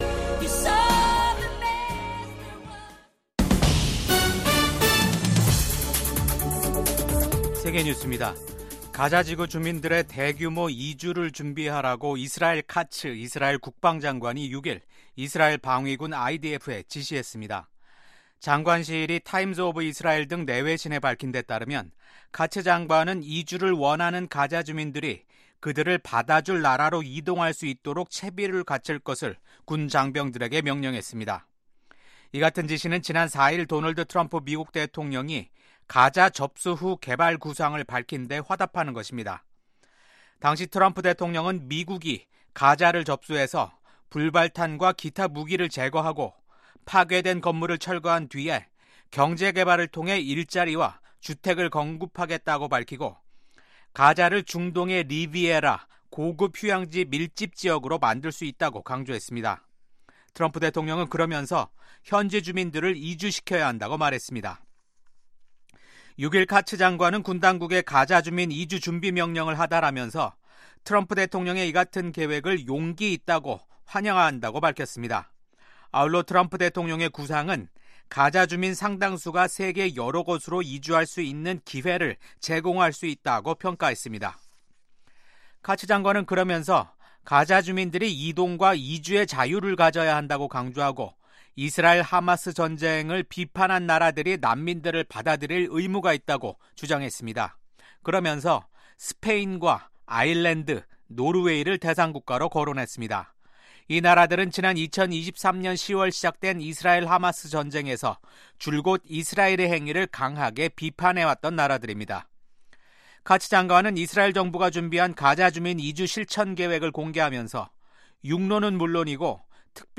VOA 한국어 아침 뉴스 프로그램 '워싱턴 뉴스 광장'입니다. 미국의 도널드 트럼프 행정부 출범으로 냉랭했던 북중 관계에 일정한 변화가 나타날 수 있다는 관측이 제기됩니다. 미국 의회에서 코리아코커스 공동의장을 맡고 있는 의원들이 트럼프 행정부가 들어서면서 한국이 미국과 에너지 협력을 확대하는 데 유리한 환경이 조성됐다는 초당적인 입장을 밝혔습니다.